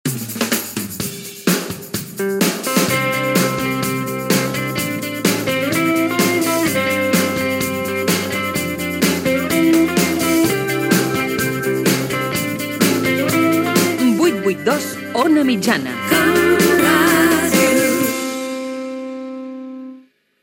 Indiactiu de la ràdio i freqüència de l'ona mitjana.